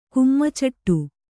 ♪ kummacaṭṭu